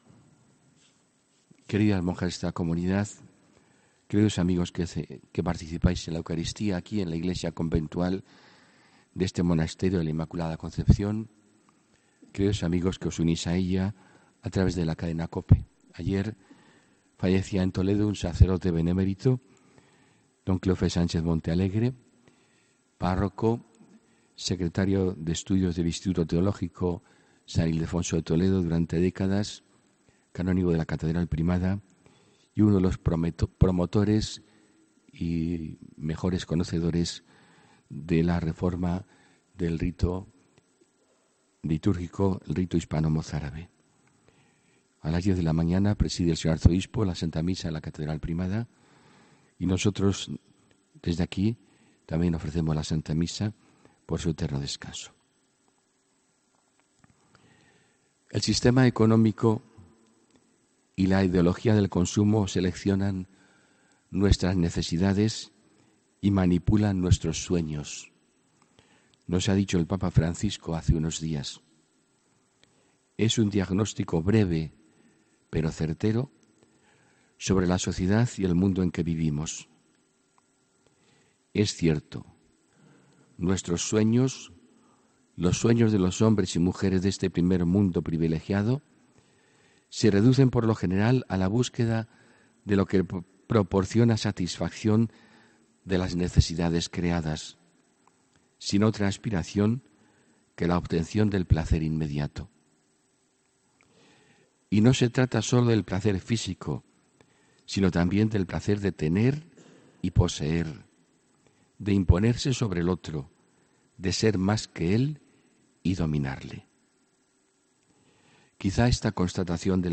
HOMILÍA 20 ENERO 2019